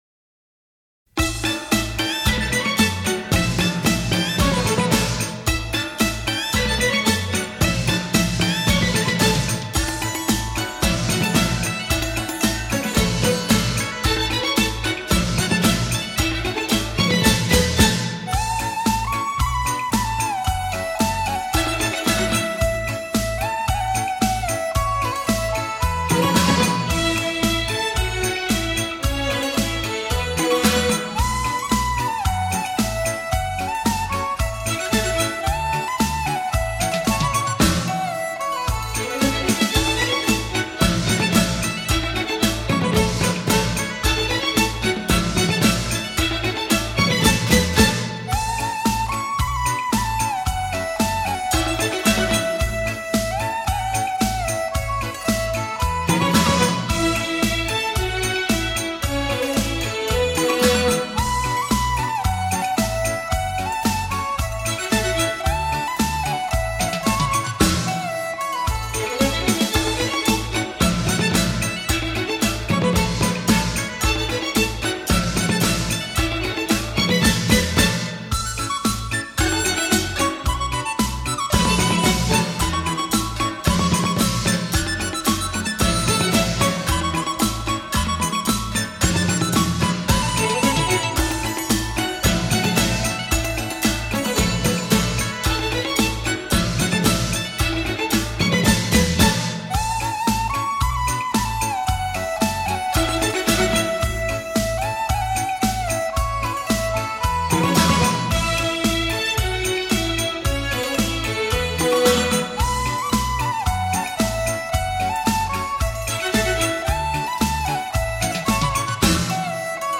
传统新年音乐精典